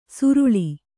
♪ suruḷi